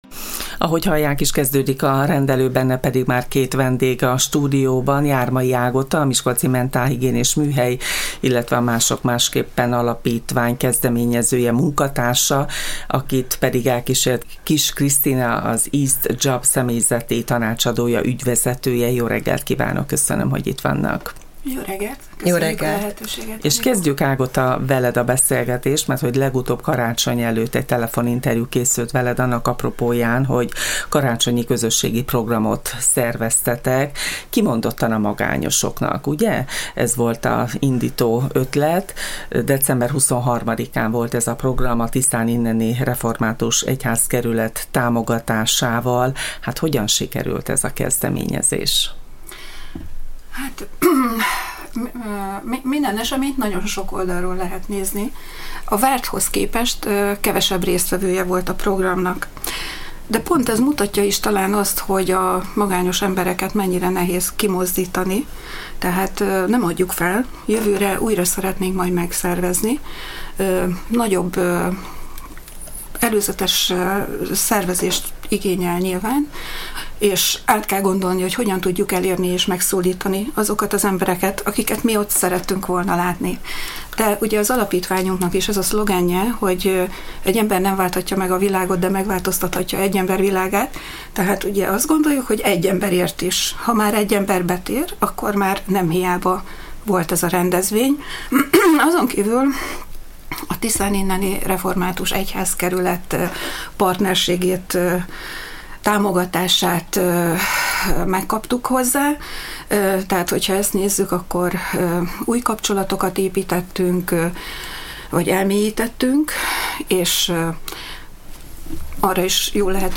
egy formálódó partneri együttműködés apropóján érkeztek együtt a Csillagpont Rádió stúdiójába. A közös nevező a társadalmi felelősségvállalás a lelki egészségünk, jóllétünk érdekében.